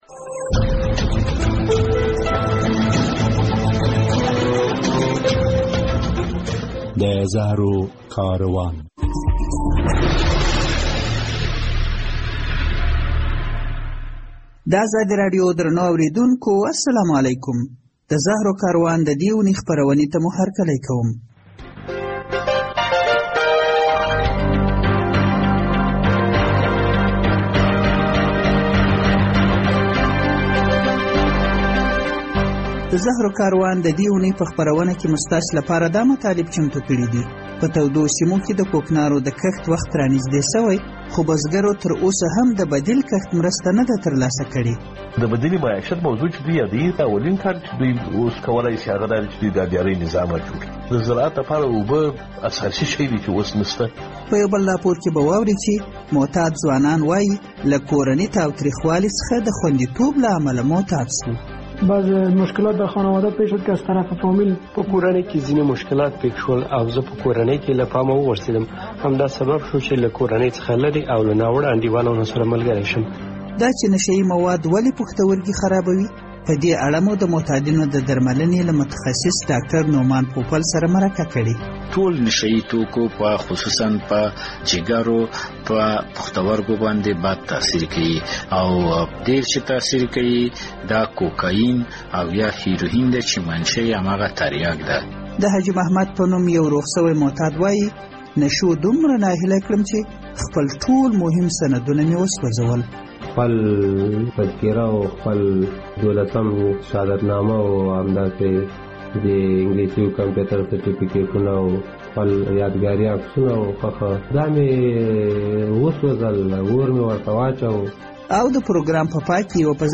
د زهرو کاروان د دې اوونۍ په خپرونه کې به واورئ چې تر راتلونکو دوو اوونیو د افغانستان په تودو سیمو کې د کوکنارو د کښت نوی فصل پیلېږي. ولې یو شمېر ځوانان نشو ته مخه کوي؟ له یوه ډاکتر سره مرکه شوې او ترې پوښتل شوي چې ولې معتادین د بډوډو له روغتیایي ستونزې سره مخ وي؟